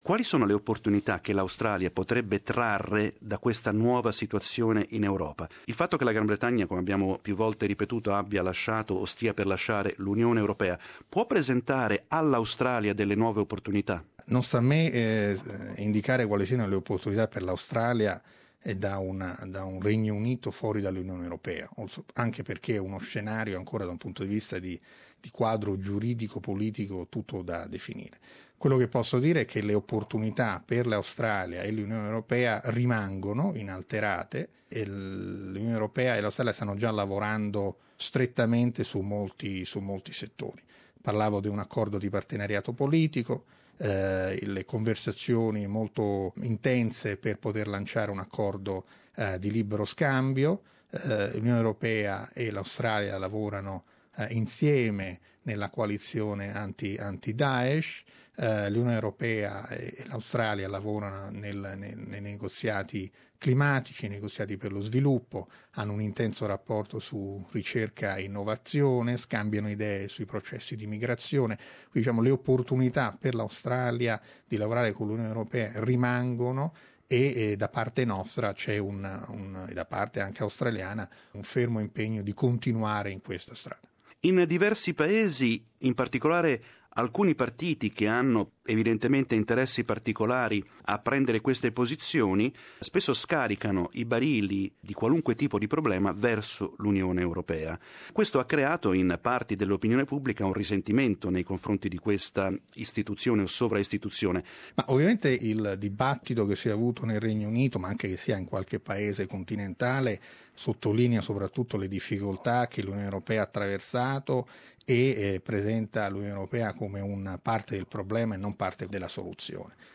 L'ambasciatore dell'Unione Europea in Australia e Nuova Zelanda, Sem Fabrizi, sta per festeggiare il terzo anno alla guida della sede diplomatica del Vecchio Continente a Canberra. In questa intervista parla di temi internazionali, ma anche della sua vita in quella che da tre anni è la sua città di residenza: Canberra.